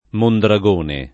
[ mondra g1 ne ]